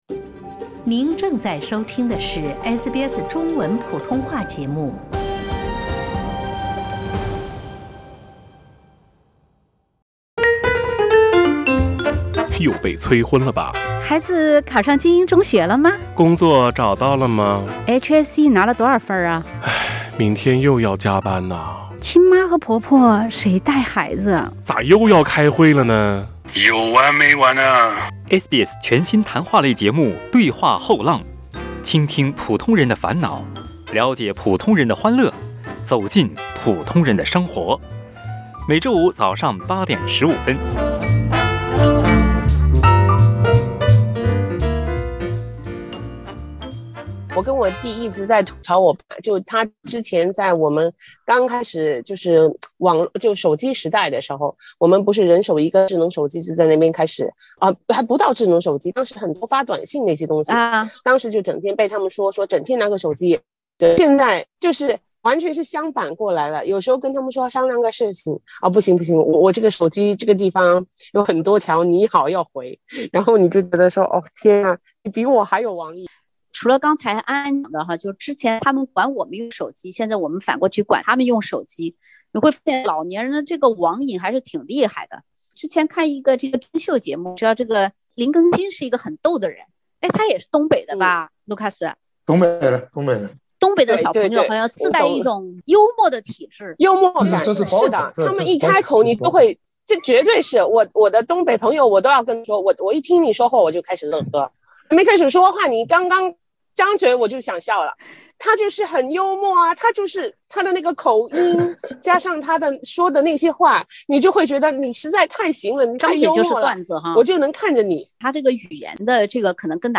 欢迎收听澳大利亚最亲民的中文聊天类节目-《对话后浪》。